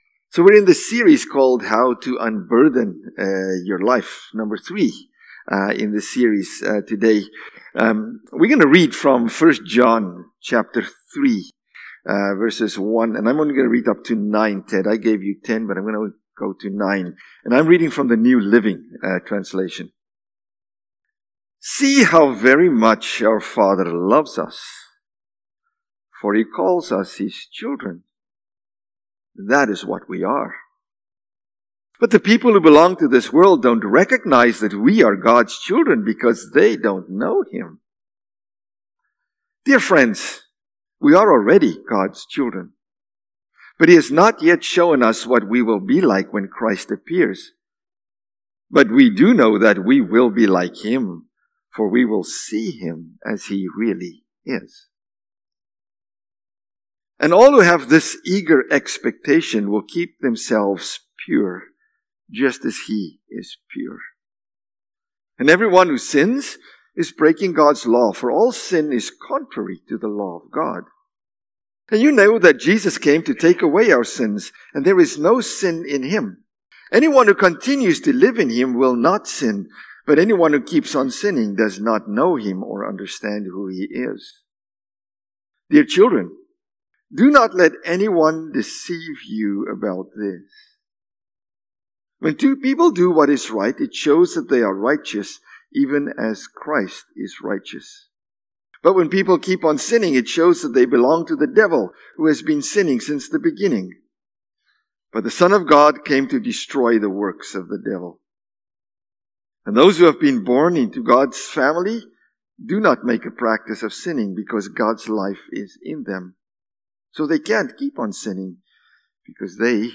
October-6-Sermon.mp3